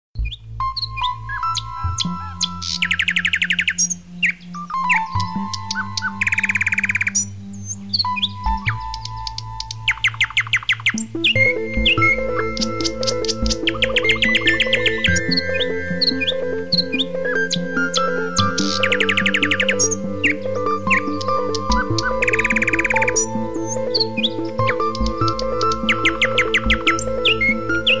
Tierstimmen